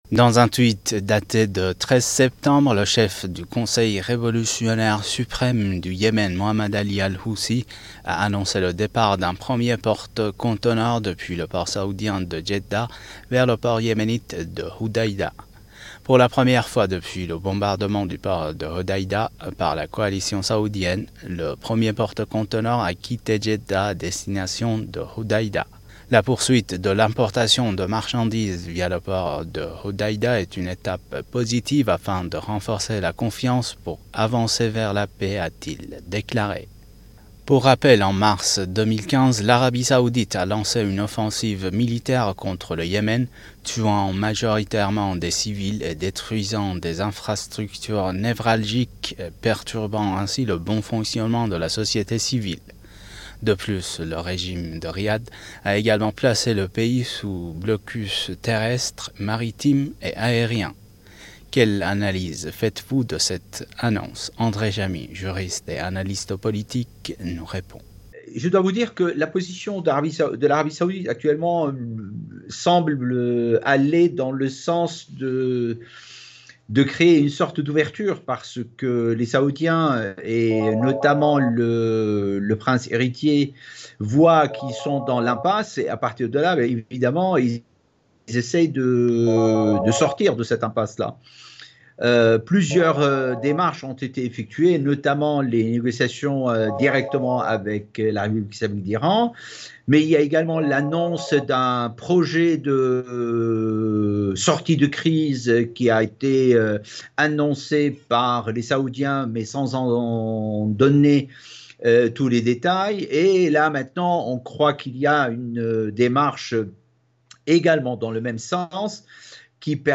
Infos / Asie de l'Ouest